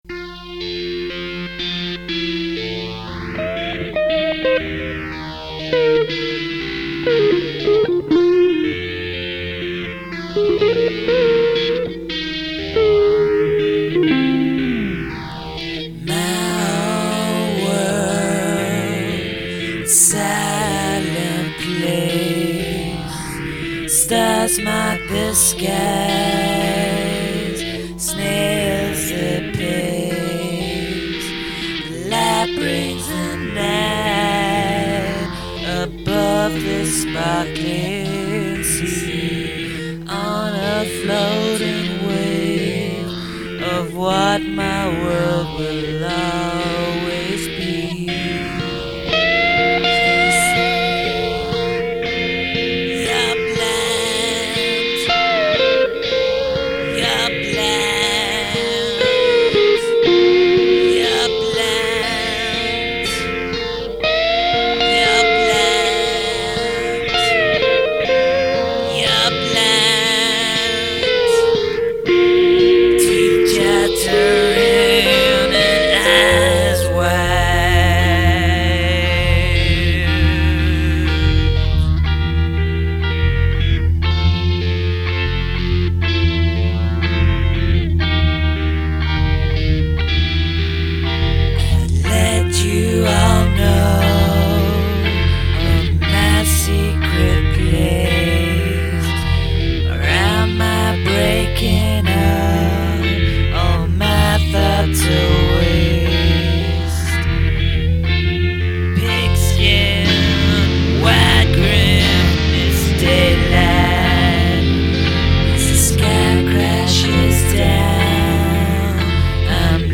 Experimental
Rock & Roll
Blues